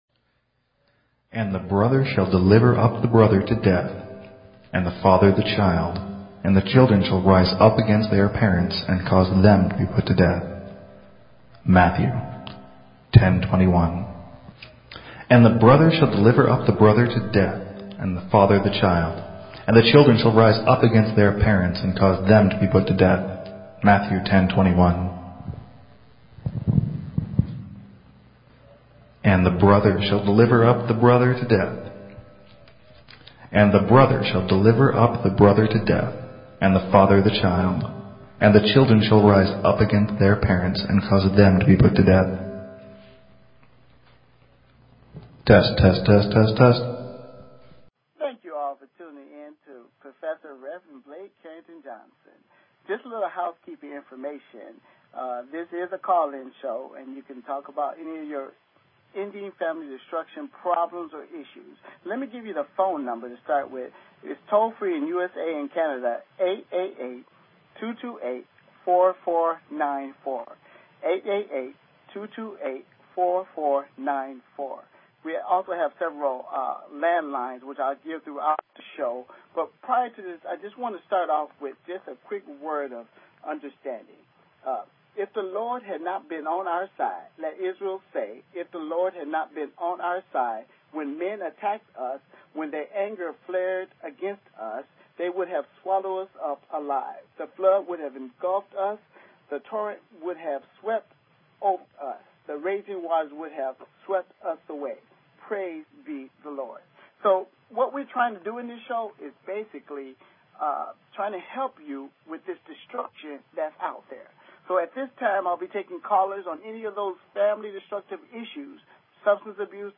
Talk Show Episode, Audio Podcast, Ending_Family_Destruction and Courtesy of BBS Radio on , show guests , about , categorized as